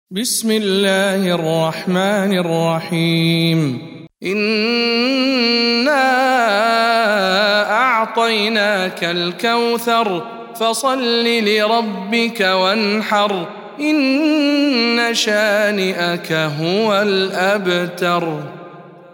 سورة الكوثر- رواية ابن ذكوان عن ابن عامر